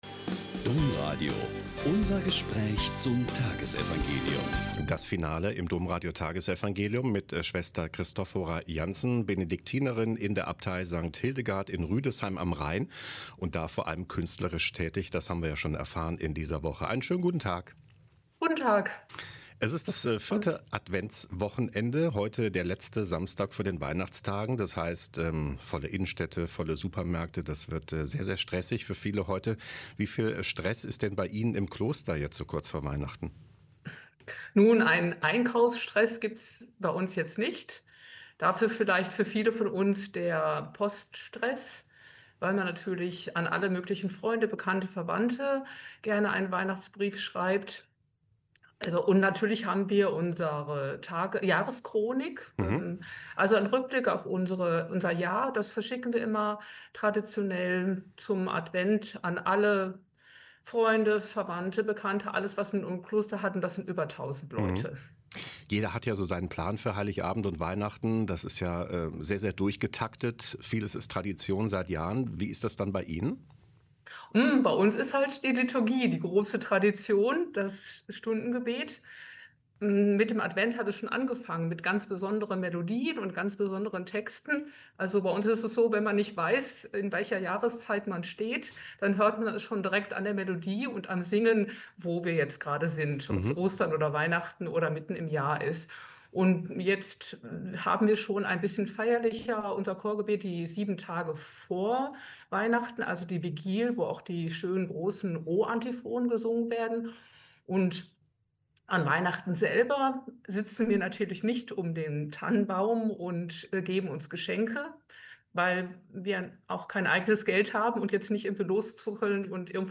Lk 1,26-38 - Gespräch